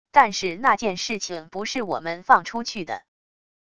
但是那件事情不是我们放出去的――wav音频生成系统WAV Audio Player